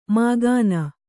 ♪ māgāna